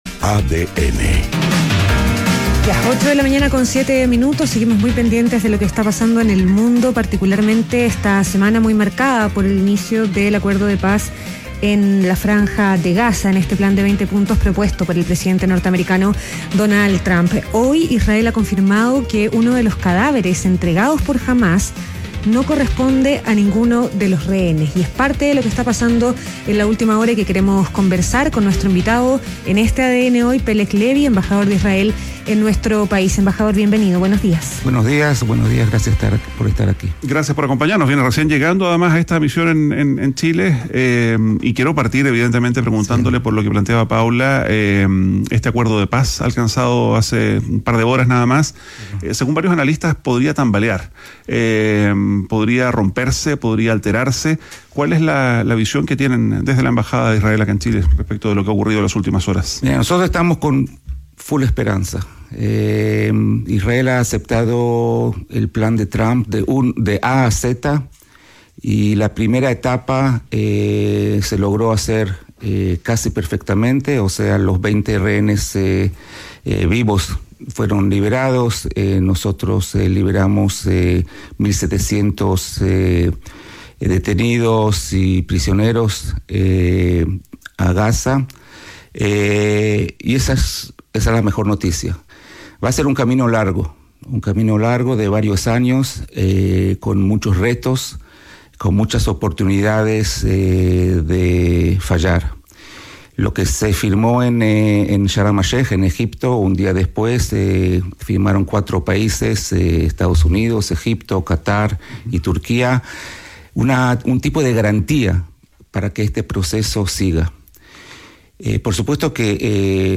Entrevista a Peleg Lewi, embajador de Israel en Chile - ADN Hoy